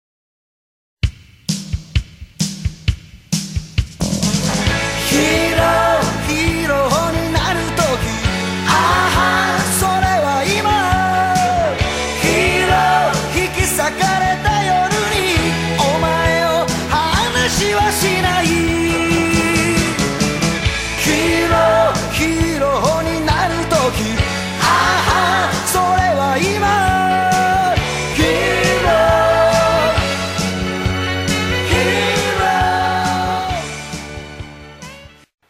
00-A-EntryMarch.mp3